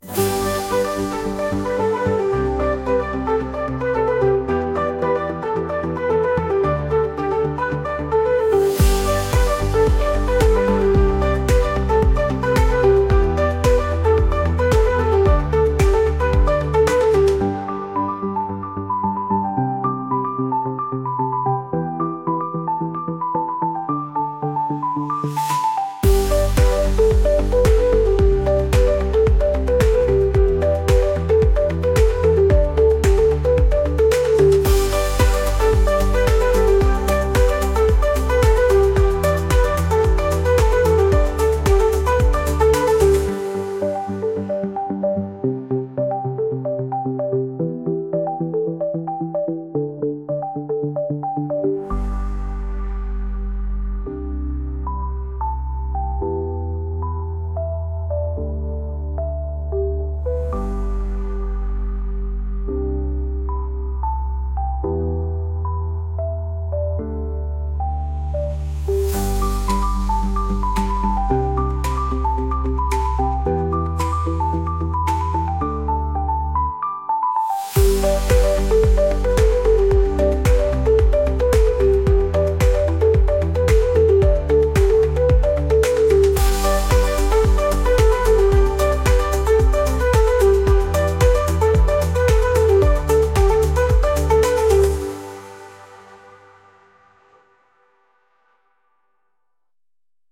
「明るい」